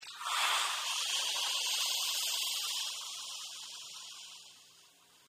Звуки пара